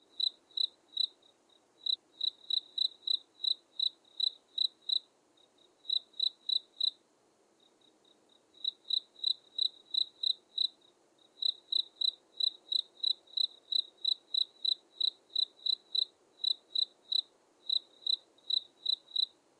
insectnight_5.ogg